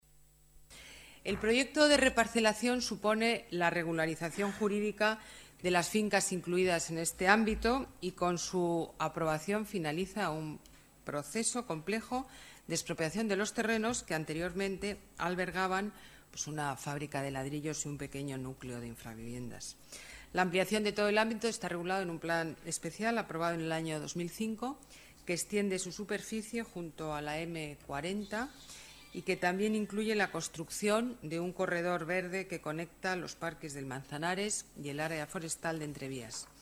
Nueva ventana:Declaraciones alcaldesa Madrid, Ana Botella: nuevas naves Mercamadrid